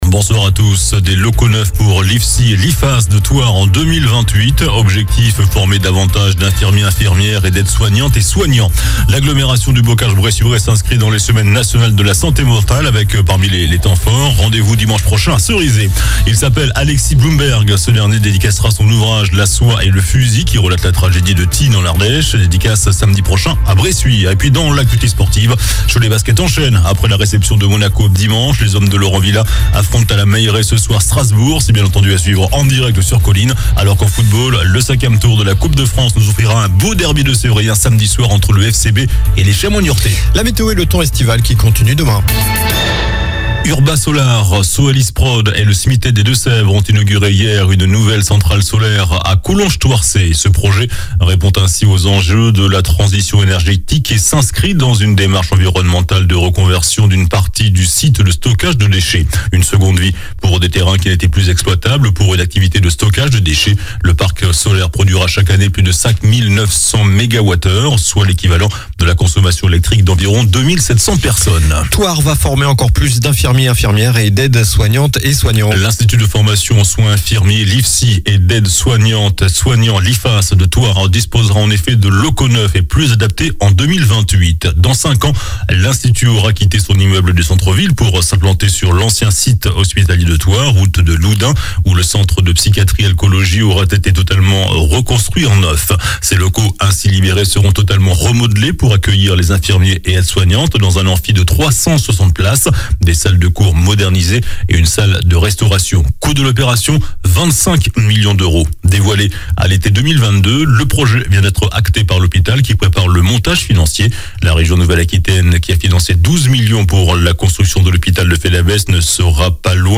JOURNAL DU MARDI 10 OCTOBRE ( SOIR )